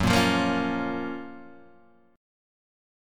GbM7 chord